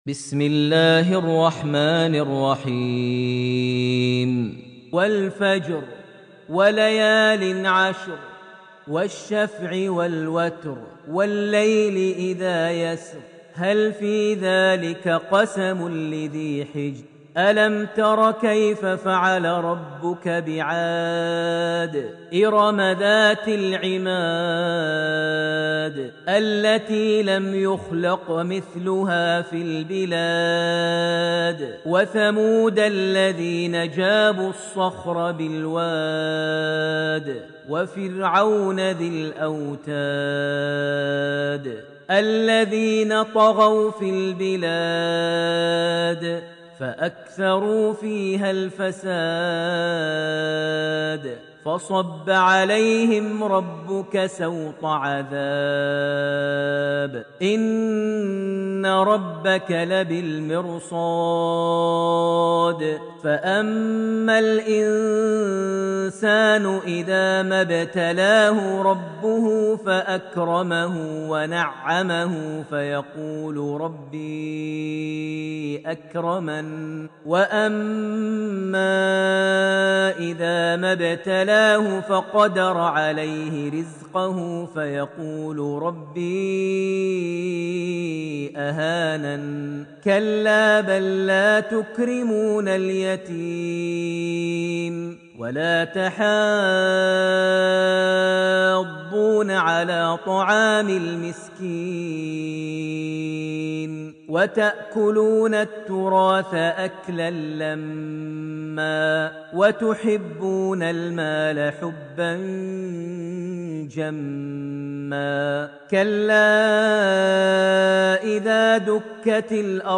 Surah Al-Fajr > Almushaf > Mushaf - Maher Almuaiqly Recitations